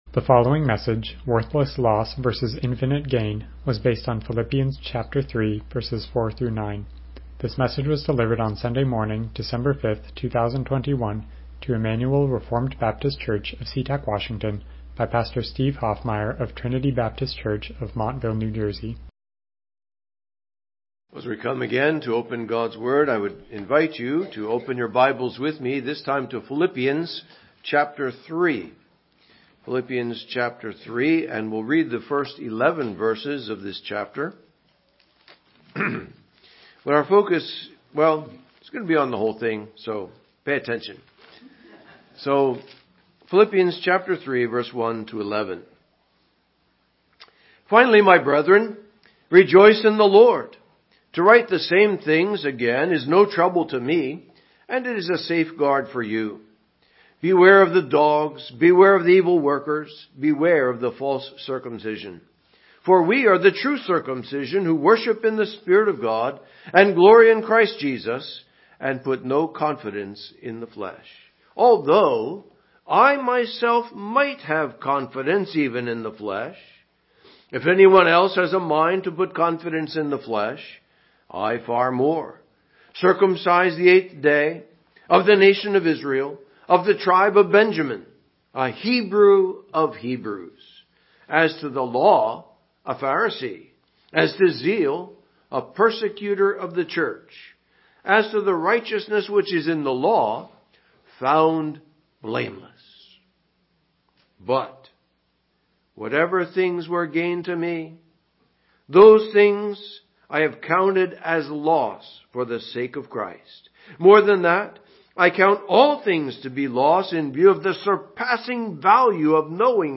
Philippians 3:4-9 Service Type: Morning Worship « Right Thinking